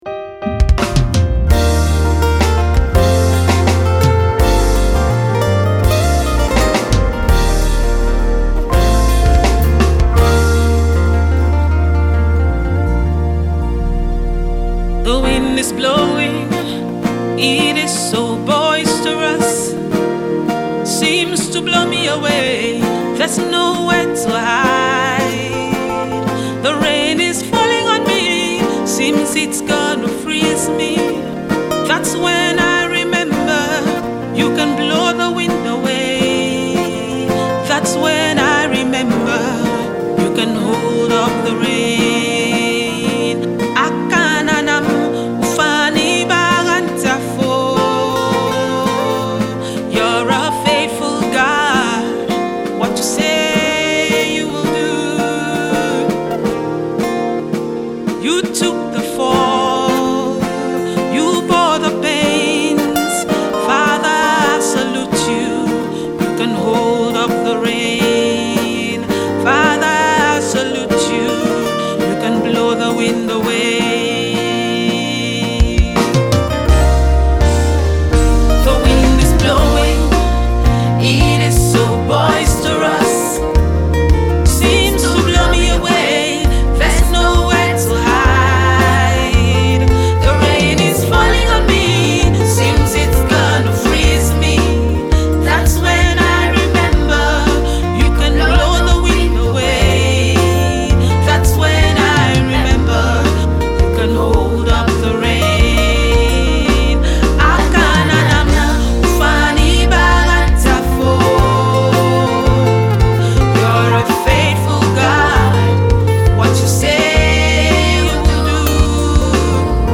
Nigeria Renowned gospel choir group
group of men and women